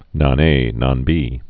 (nŏn-ā nŏn-bē)